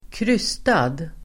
Ladda ner uttalet
Uttal: [²kr'ys:tad]